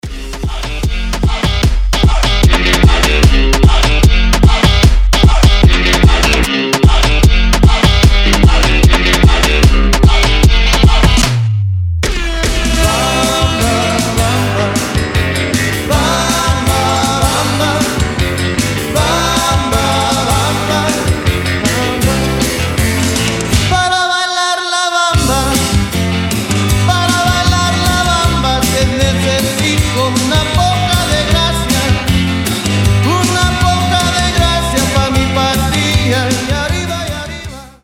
• Качество: 320, Stereo
зажигательные
Moombahton
латина
ремиксы